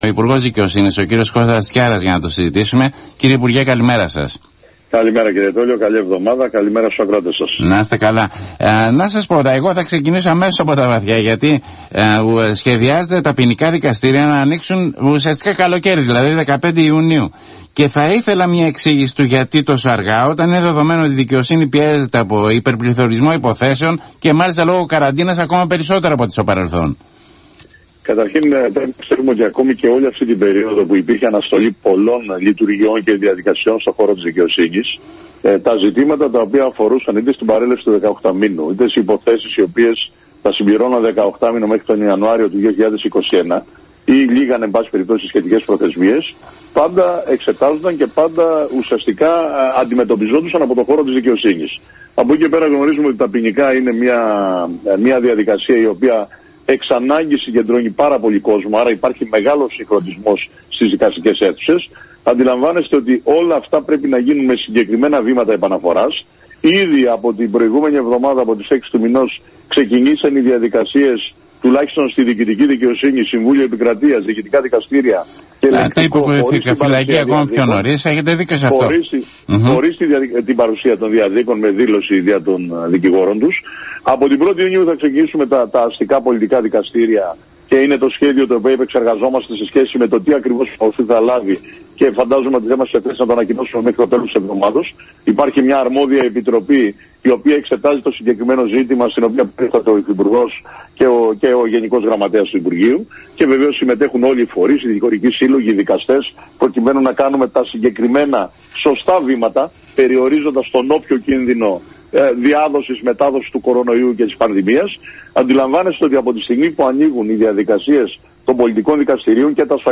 Εφ όλης της ύλης συνέντευξη έδωσε ο Υπουργός Δικαιοσύνης Κώστας Τσιάρας